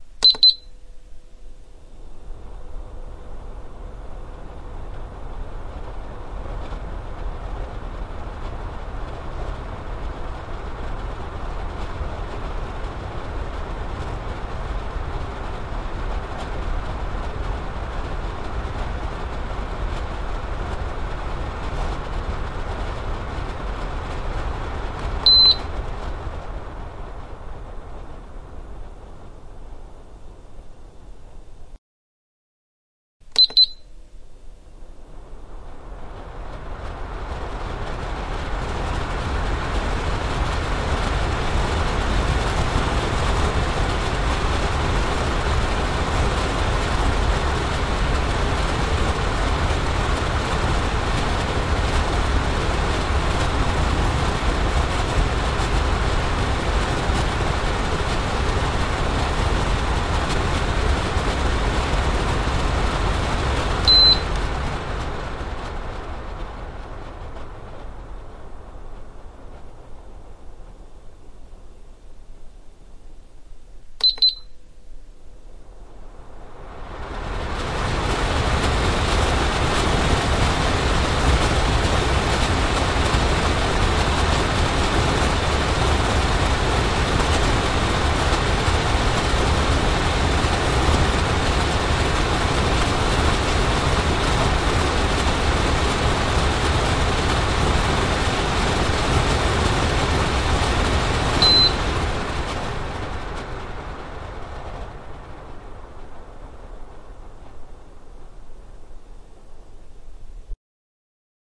На этой странице собраны звуки работающего очистителя воздуха – монотонный белый шум, напоминающий легкий ветер.
Звук движения воздуха сквозь очиститель